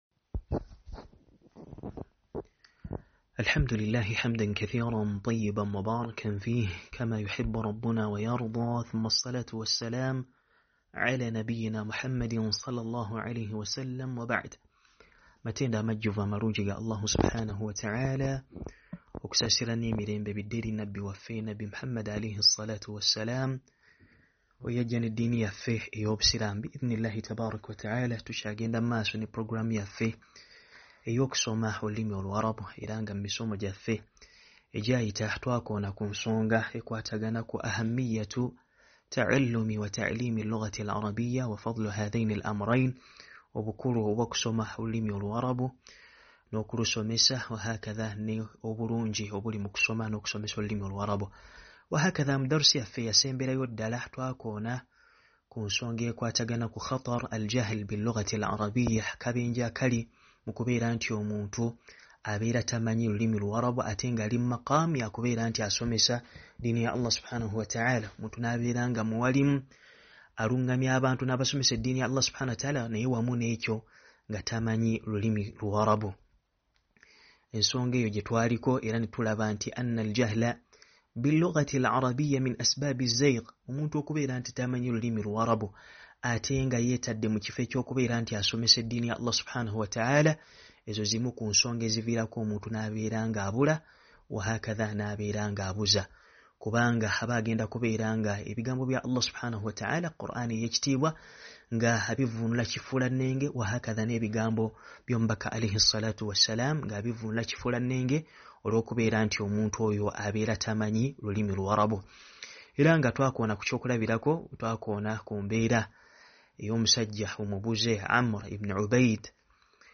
Wuliliza Emisomo gya ba ma sheikh be uganda